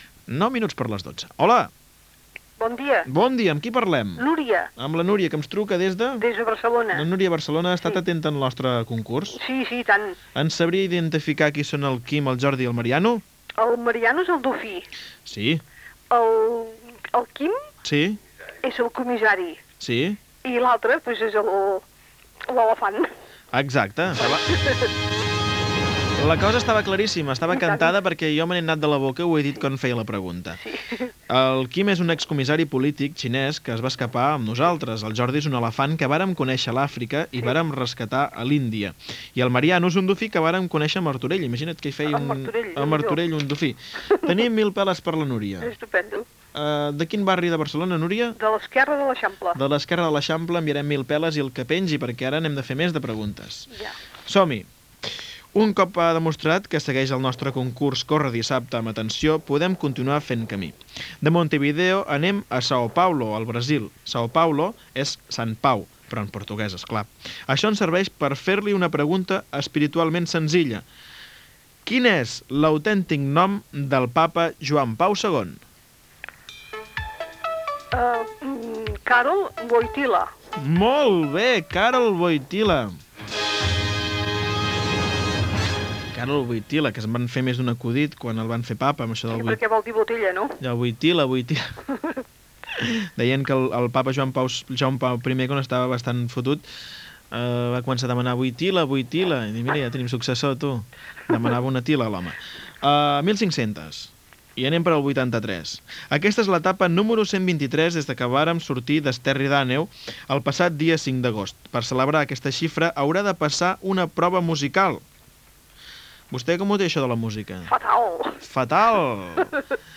Concurs telefònic i identificació del programa
Info-entreteniment